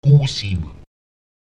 Lautsprecher gúsim [Èguùsim] heilen